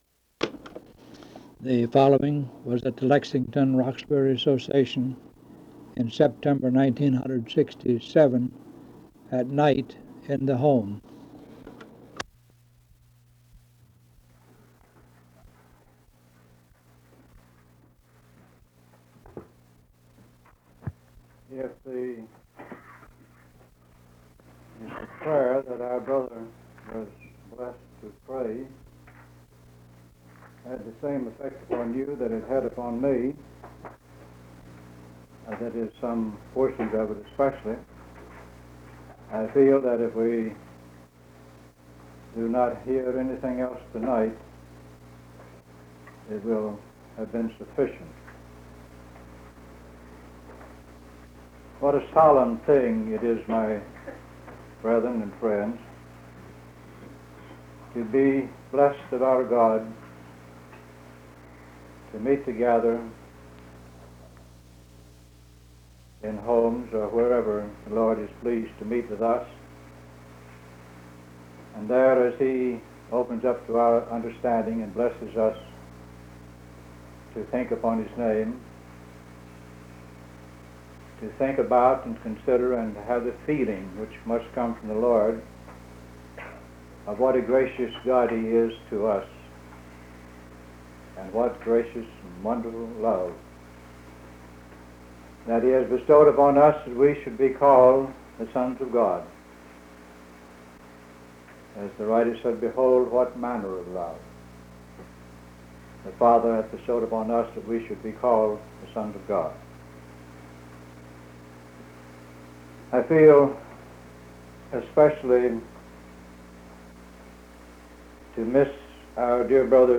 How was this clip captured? Sermon from the 1967 Lexington-Roxbury Association "at night at the home"